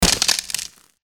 snd_groundcrack.wav